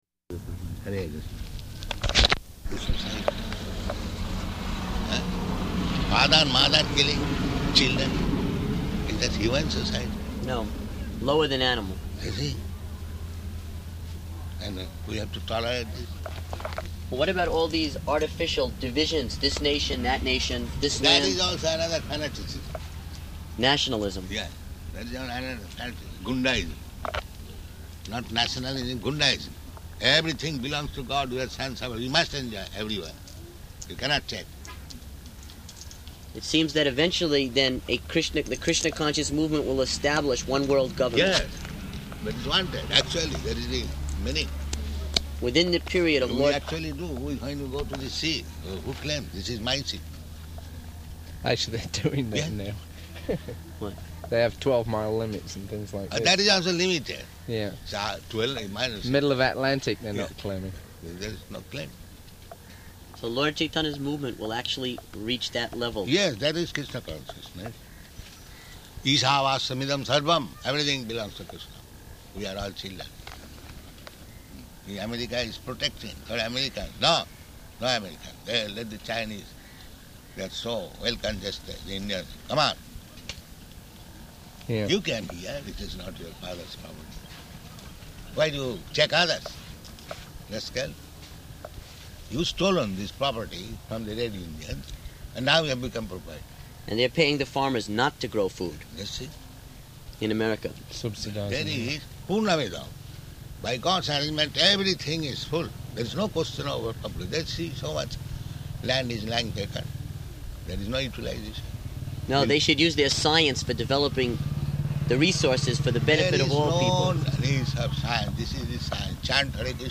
Room Conversation
Location: Bhubaneswar